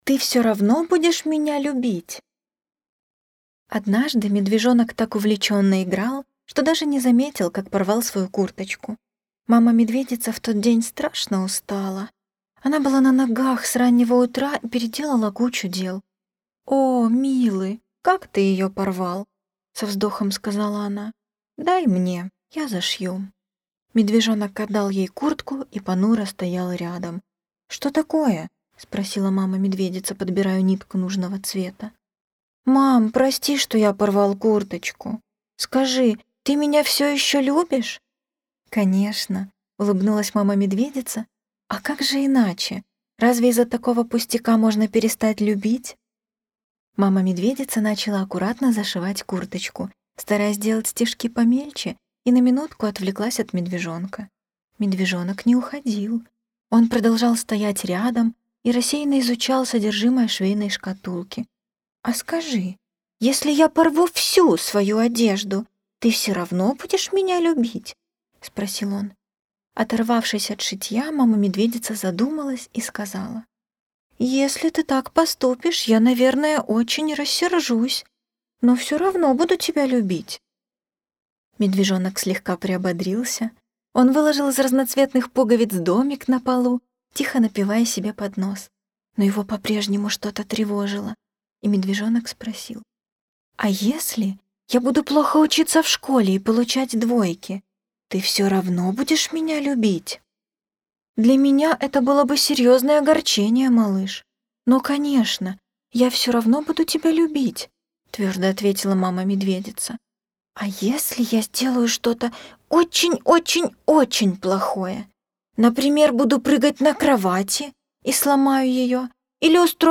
Ты все равно будешь меня любить? - аудиосказка - слушать онлайн